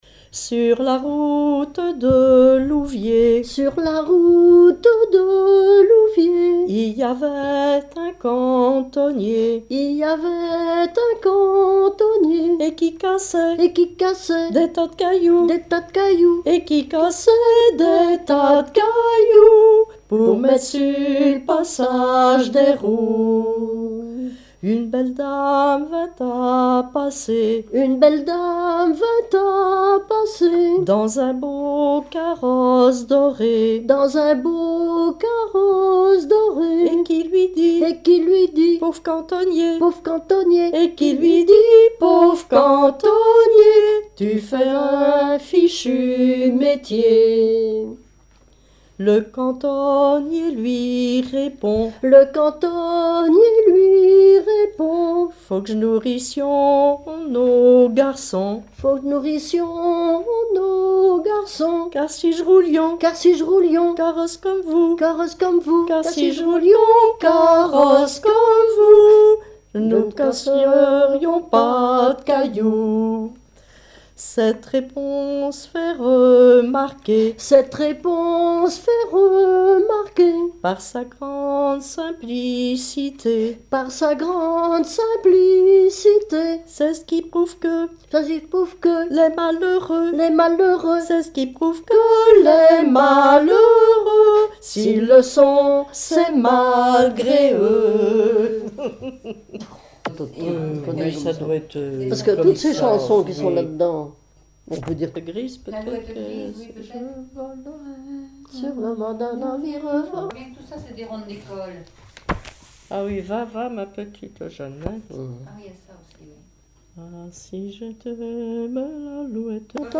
Mémoires et Patrimoines vivants - RaddO est une base de données d'archives iconographiques et sonores.
chantée en duo
Genre laisse
Pièce musicale inédite